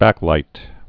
(băklīt)